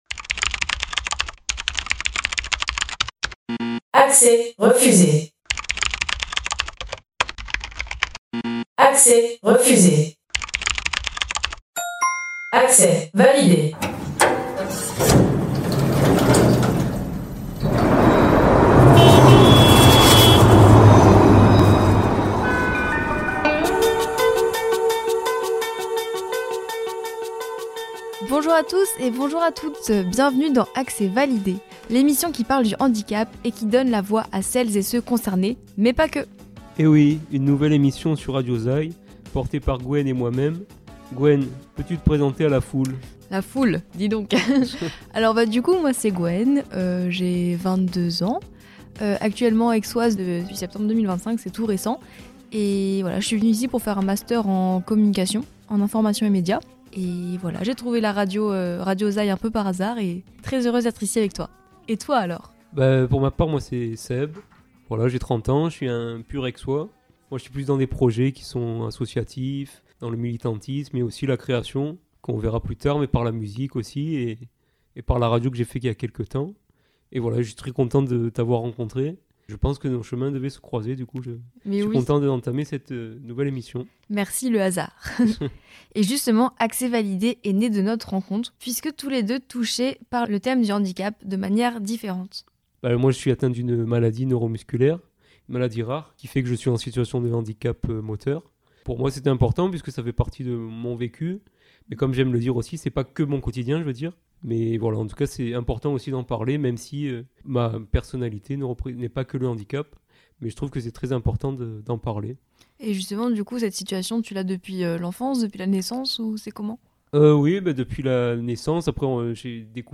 Ce premier épisode est un mélange de présentation, de rencontres et d'échanges avec des Aixois et d'un coup de cœur musical.